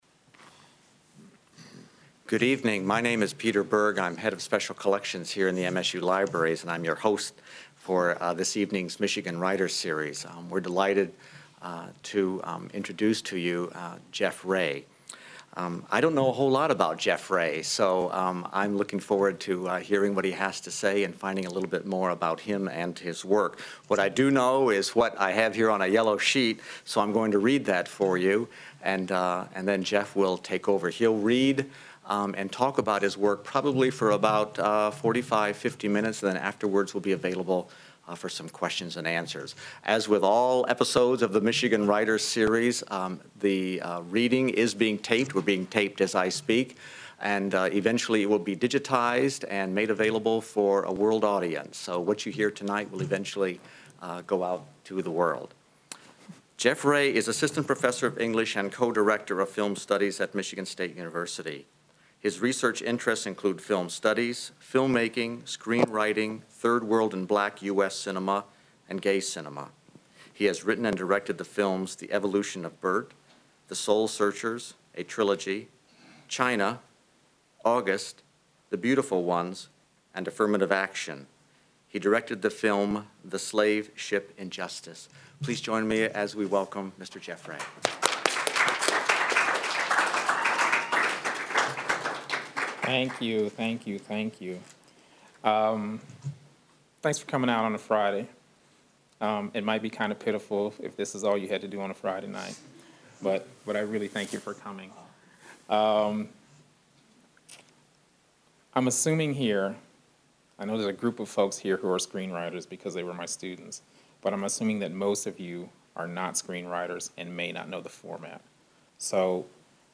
reads his selected works